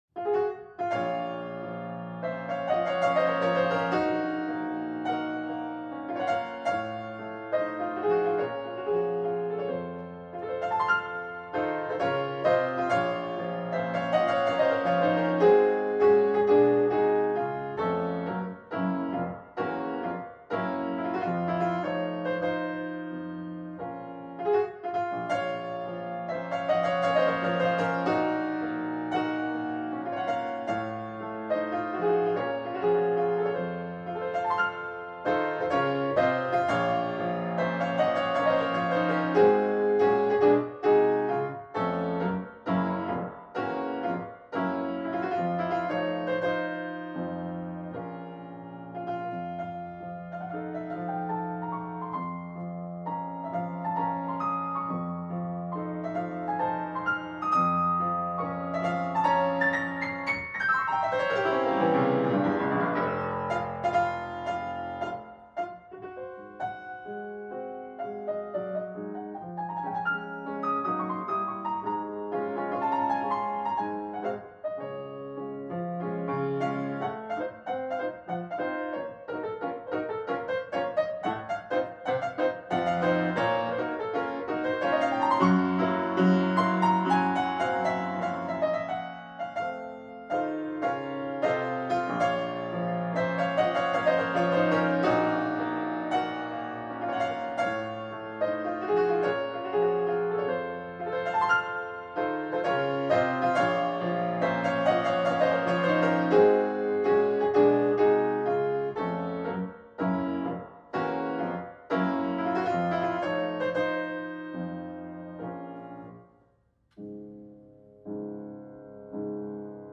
No parts available for this pieces as it is for solo piano.
Piano  (View more Advanced Piano Music)
Classical (View more Classical Piano Music)